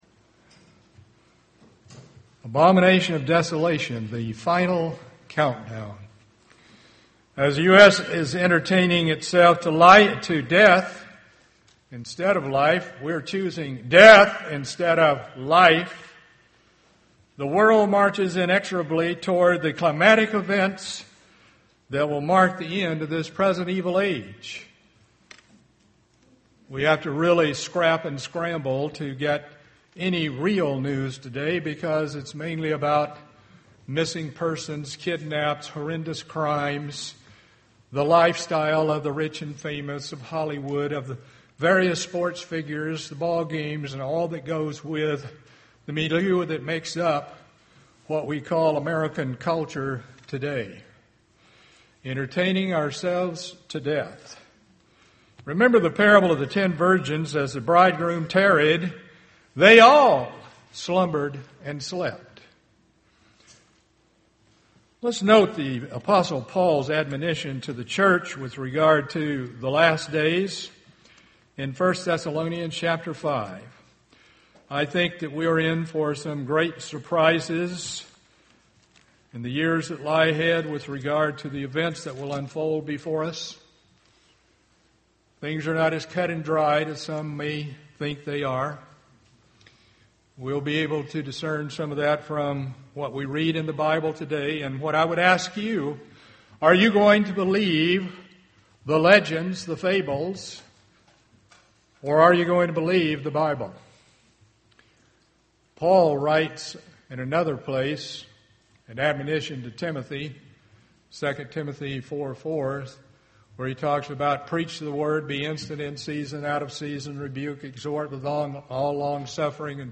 Find the answers to these questions in this sermon.